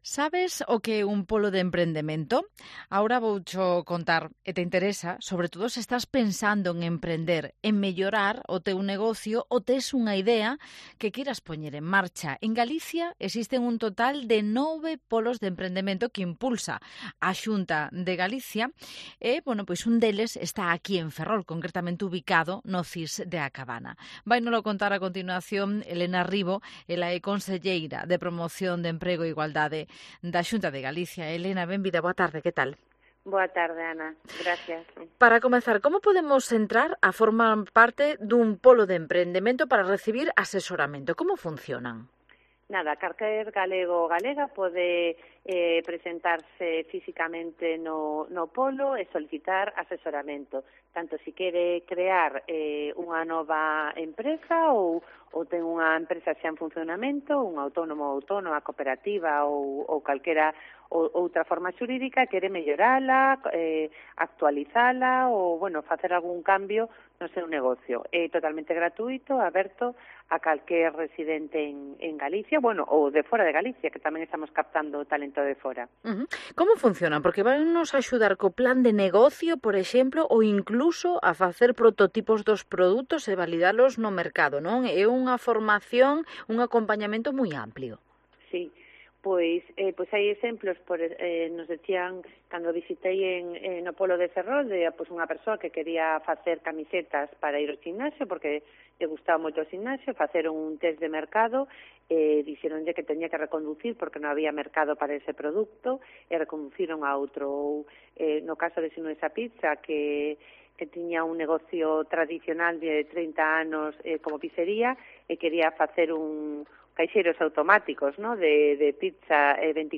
Elena Rivo, conselleira Promoción Económica e Igualdade de la Xunta de Galicia
¿Cómo funcionan? , nos lo contó Elena Rivo, conselleira de Promoción do Emprego e Igualdade de la Xunta de Galicia.